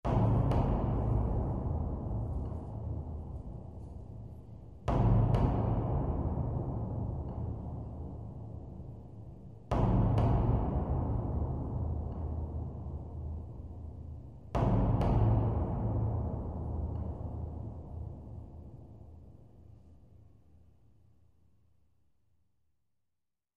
Music: Eerie Slow Bass Drum Hits.